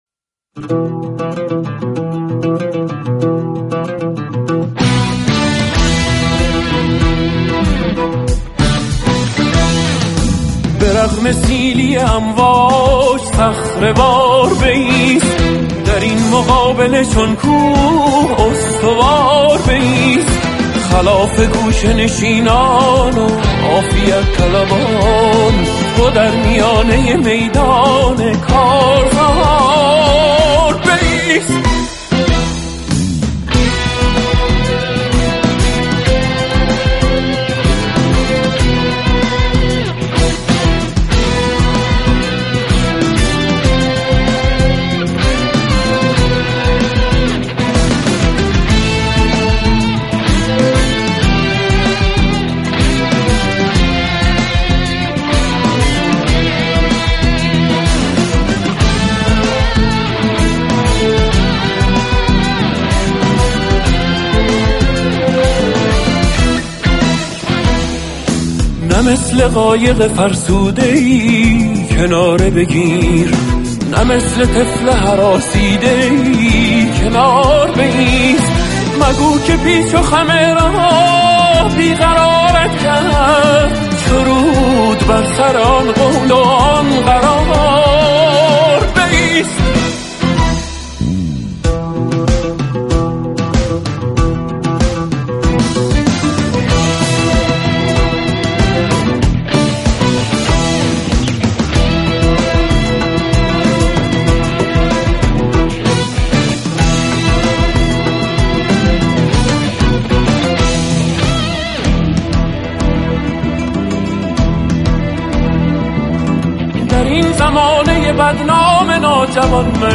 نوازندگان ویولن
نوازندگان ویولا
نوازنده عود
نوازنده گیتار الكتریك
نوازنده گیتاربیس